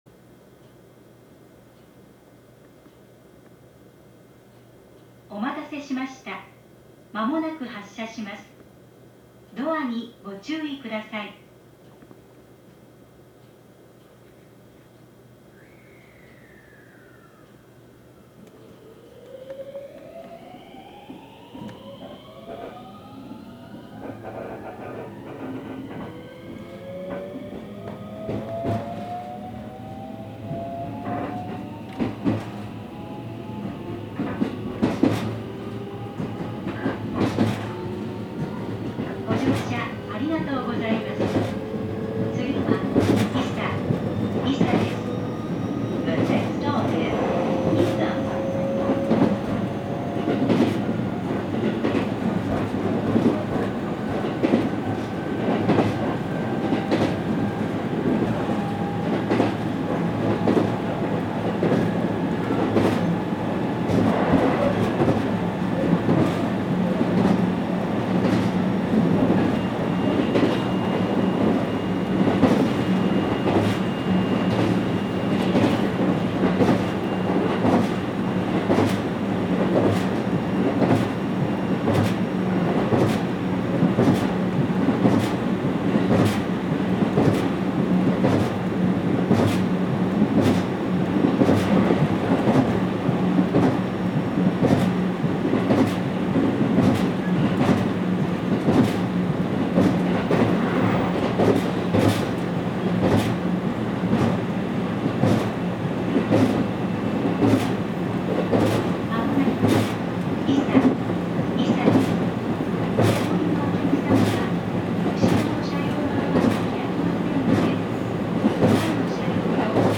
走行音
録音区間：高津～石原(お持ち帰り)